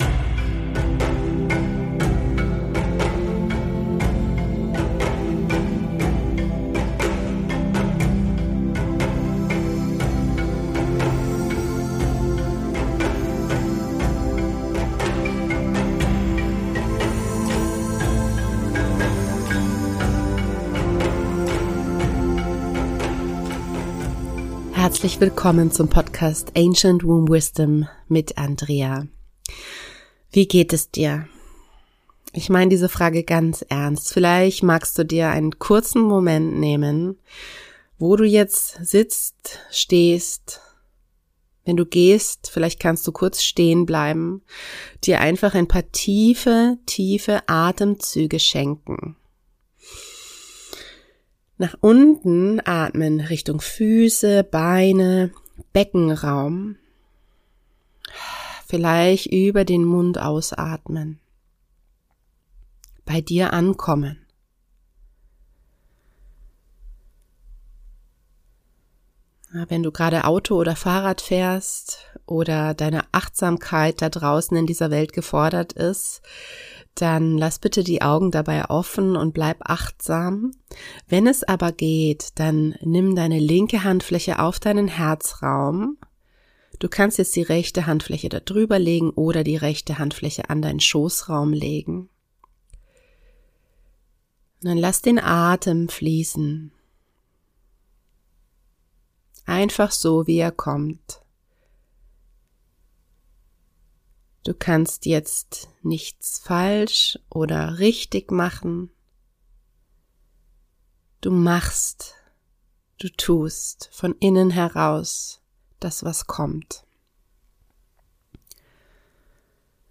In dieser Episode erwartet Dich eine geführte Meditation mit verschiedenen Teilen, die Du komplett oder Stück für Stück durchlaufen kannst.